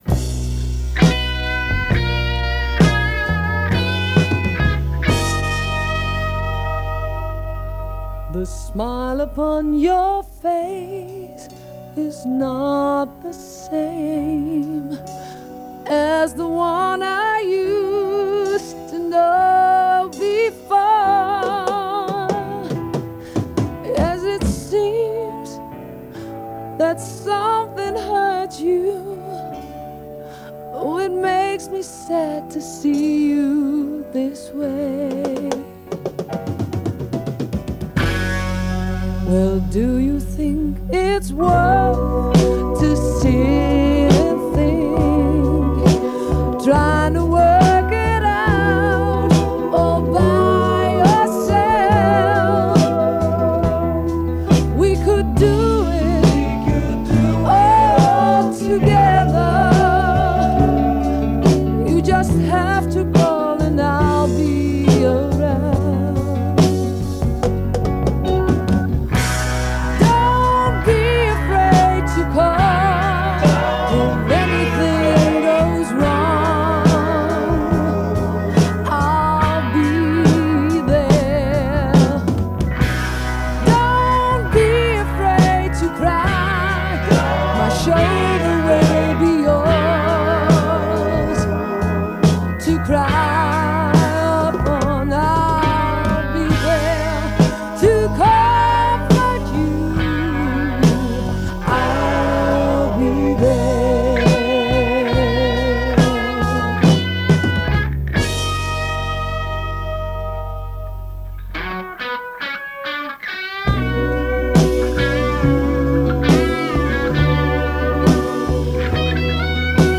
Hammond orgel